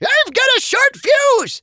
tnt_guy_start_vo_01.wav